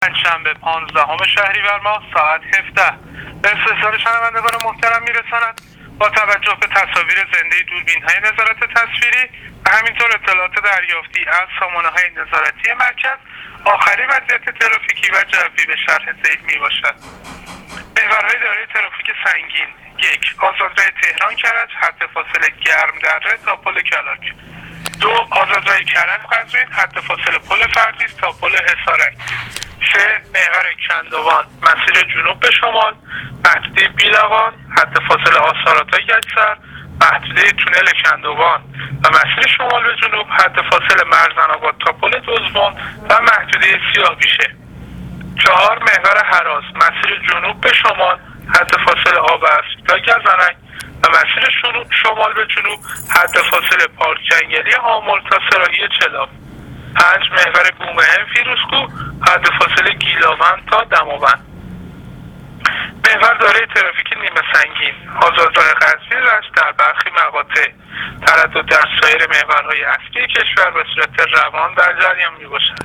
رادیو اینترنتی پایگاه خبری وزارت راه و شهرسازی: